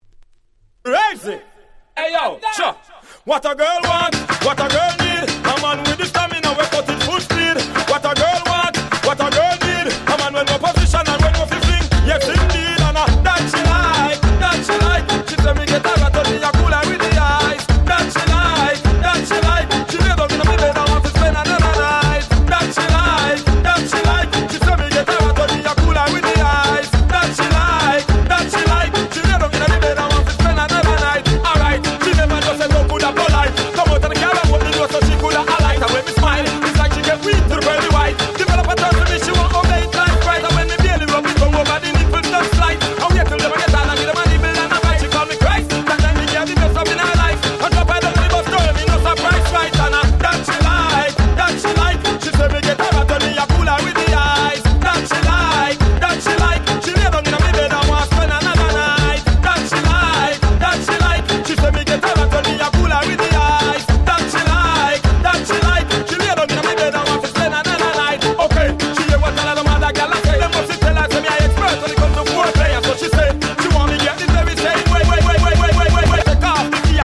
02' Super Hit Dancehall Riddim !!